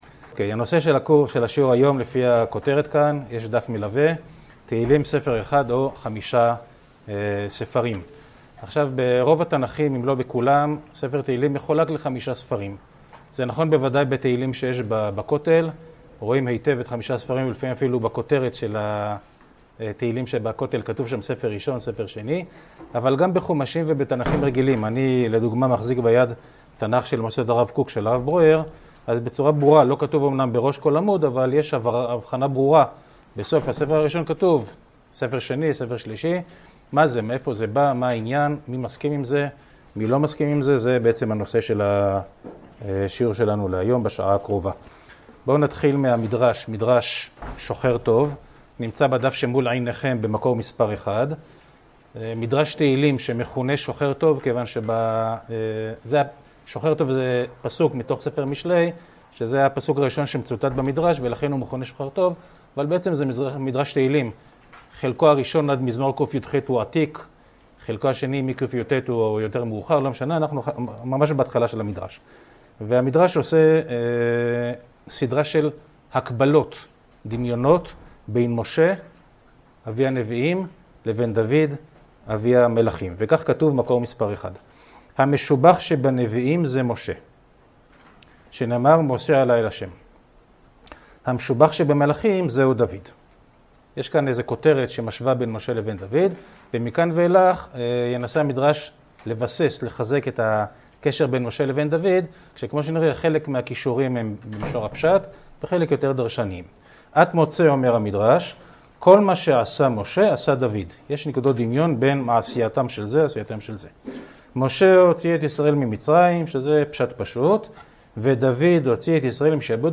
השיעור באדיבות אתר התנ"ך וניתן במסגרת ימי העיון בתנ"ך של המכללה האקדמית הרצוג תשפ"א